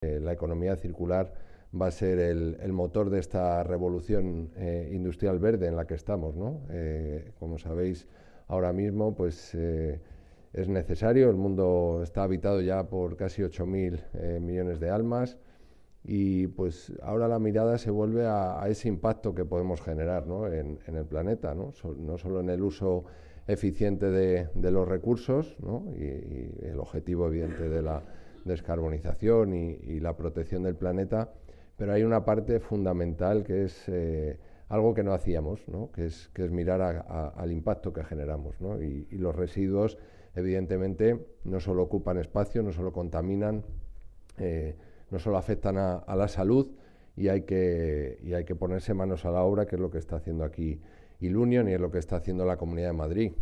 dijo Carlos Novillo formato MP3 audio(1,02 MB), consejero de Medio Ambiente, Agricultura e Interior de la Comunidad de Madrid, que presidió el acto de inauguración de la planta el pasado 25 de enero.